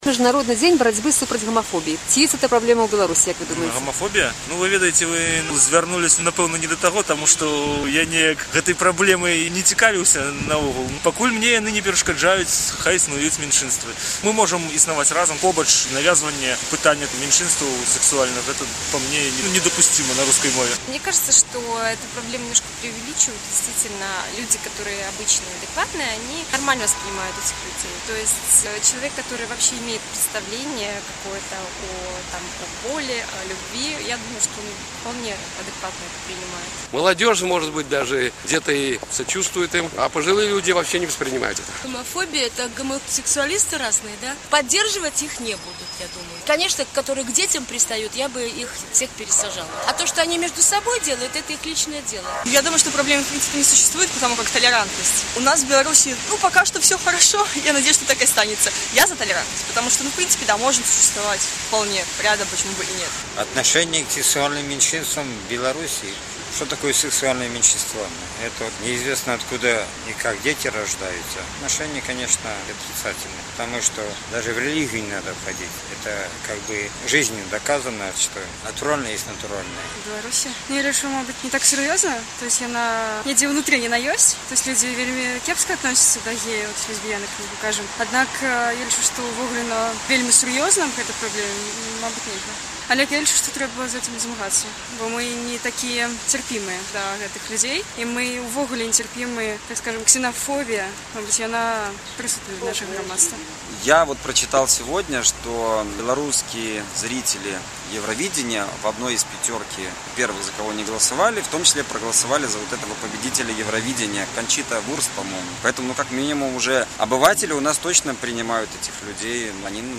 Праблему гамафобіі адчуваю на сабе, — апытаньне ў Менску